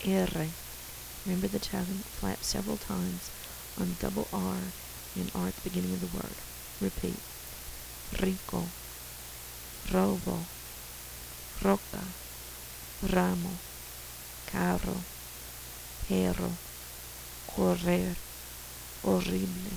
Sounds that are pretty different from English
sounds like the        sounds like a kid                     sounds like a b                sounds like an s
tt in “butter” or       playing with a car